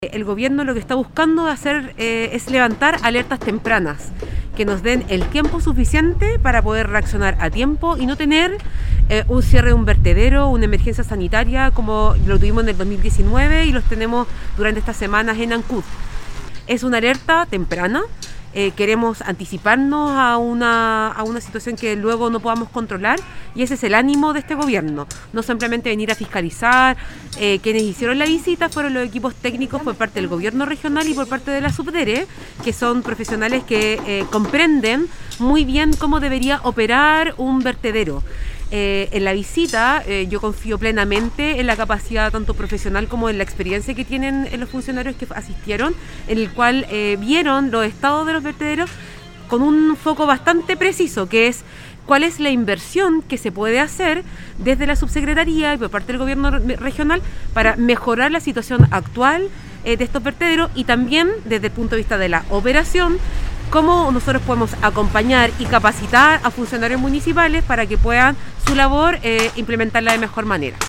En la consecución de ese objetivo, desde la subsecretaría de Desarrollo regional, la directora Los Lagos, Camila Ponce, destacó que se busca levantar alertas tempranas respecto de los vertederos en Chiloé, antes que ocurra un colapso como el que se originó en Ancud, en el sector de Huicha, cuyo cierre desencadenó esta permanente crisis en la comuna.
03-DIRE-SUBDERE-CAMILA-PONCE.mp3